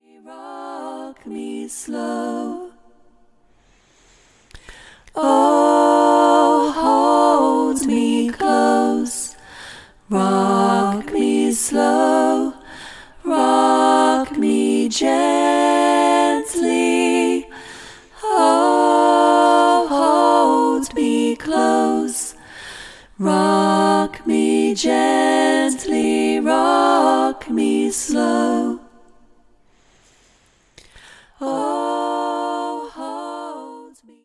A song for group singing
Parts – 3